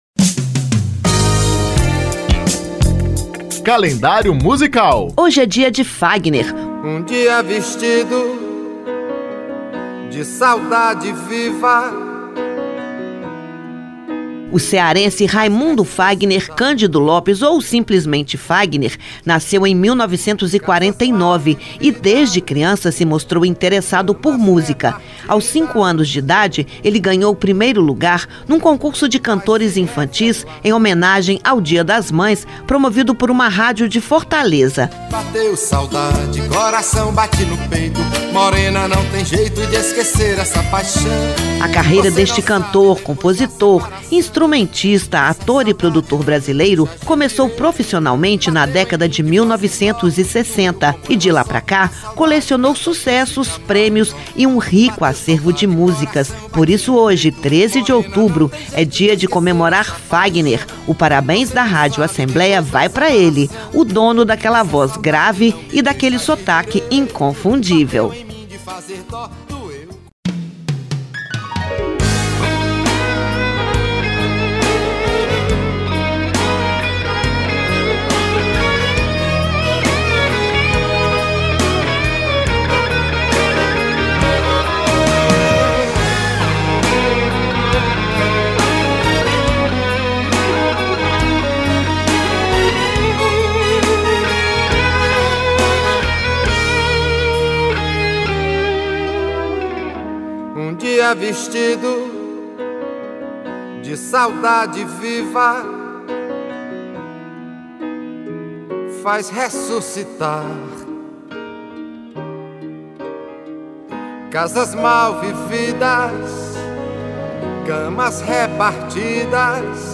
O dono daquela voz grave e daquele sotaque inconfundível coleciona sucessos, prêmios e um rico acervo de músicas.